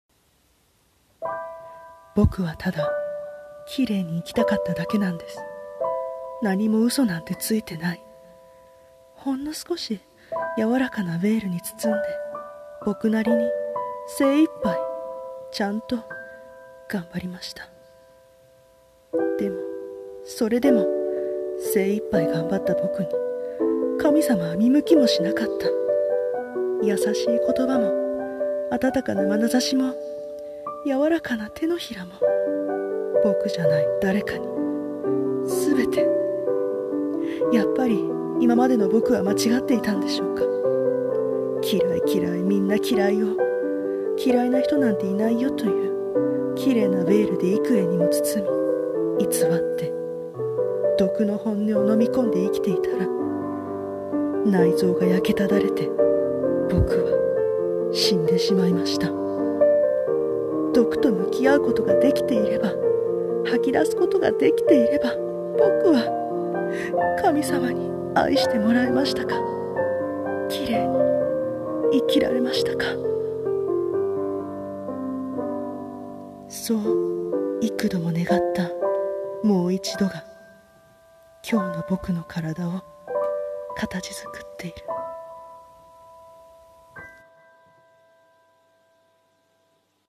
【一人声劇】夏を超えて､夢となれ。